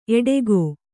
♪ eḍegō